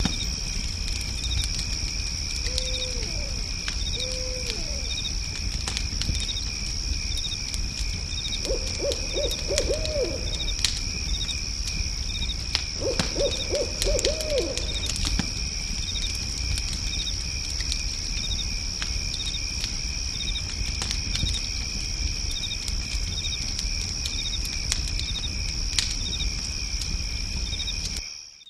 Campfire Popping Sweetener